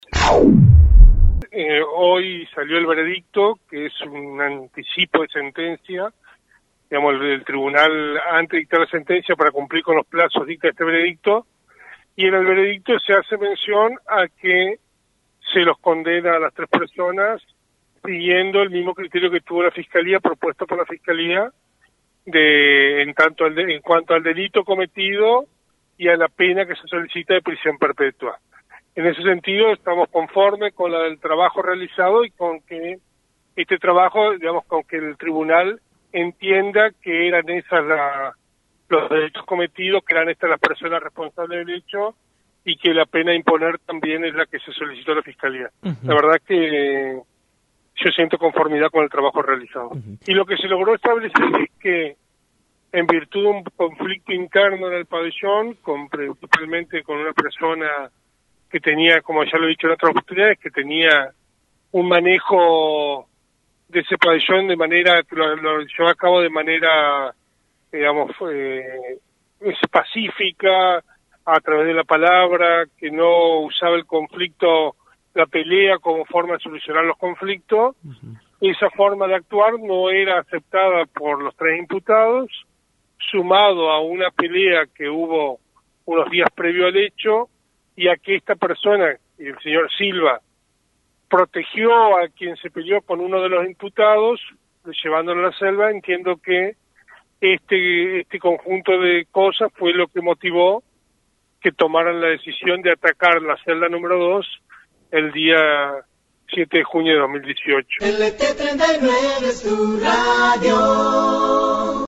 En diálogo con FM 90.3, el fiscal Eduardo Guaita se manifestó satisfecho por la sentencia, y por comprobarse los motivos del crimen.